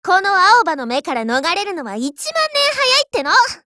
碧蓝航线:青叶语音
Cv-30303_warcry.mp3